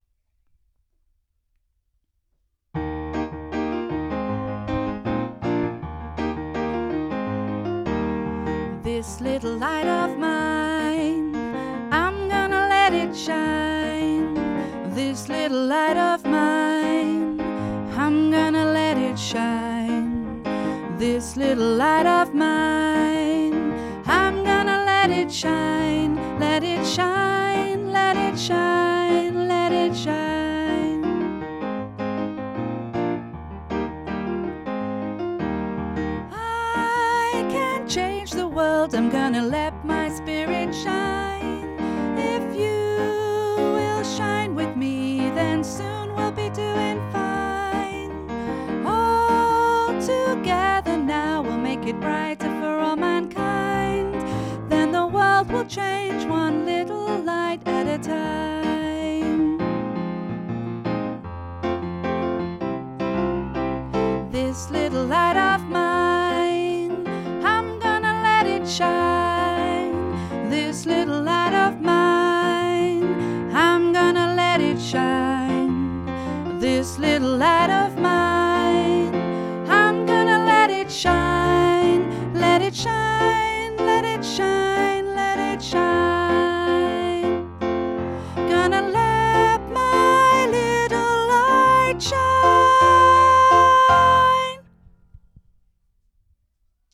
Performance Track